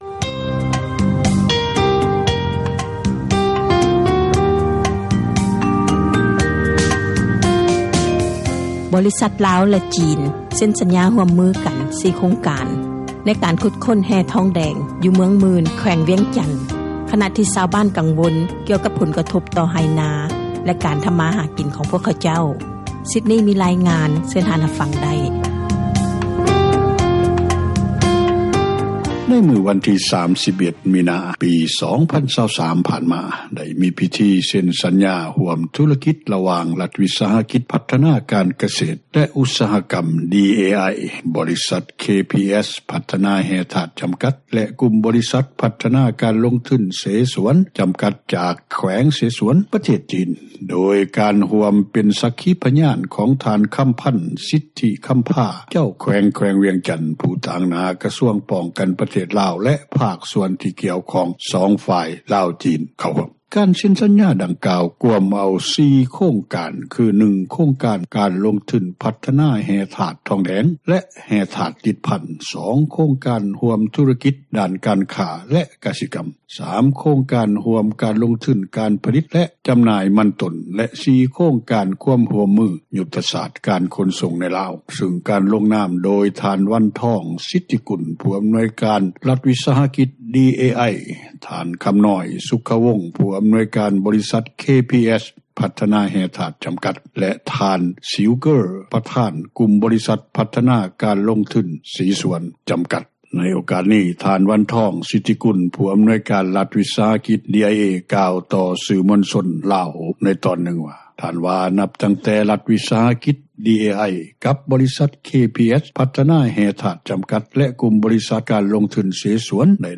ດັງທີ່ຊາວບ້ານຄົນນຶ່ງ ກ່າວຕໍ່ວິທຍຸເອເຊັຽເສຣີ ໃນມື້ວັນທີ 5 ເມສາ 2023 ນີ້ວ່າ: